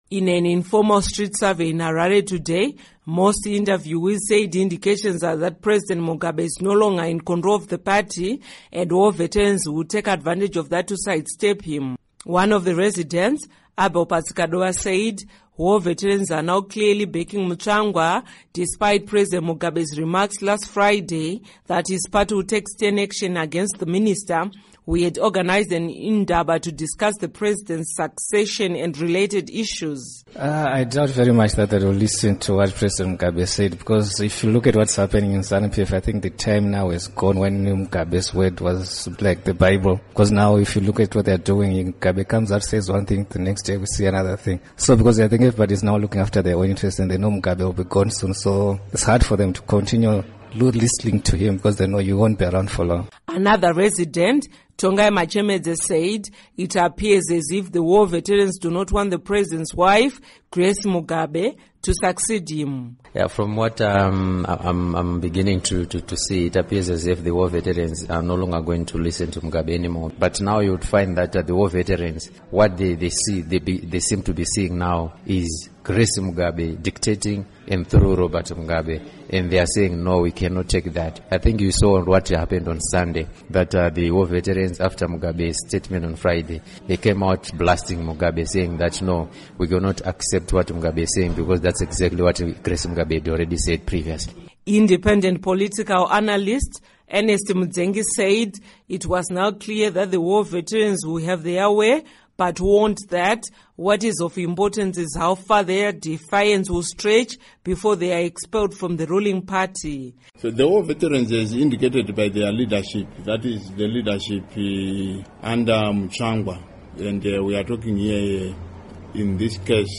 In an informal street survey in Harare today, most interviewees said indications are that President Mugabe is no longer in control of the party and war veterans will take advantage of that to side step him.
Report on Mugabe, War Veterans Clash